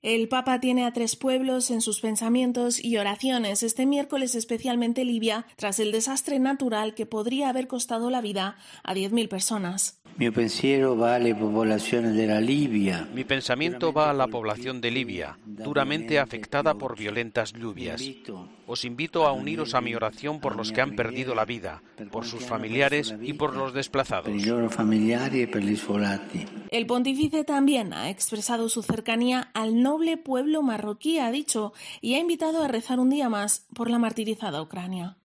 Al final de la Audiencia General, el Santo Padre ha pedido que no falte "nuestra solidaridad para estos hermanos y hermanas tan probados por estas calamidades"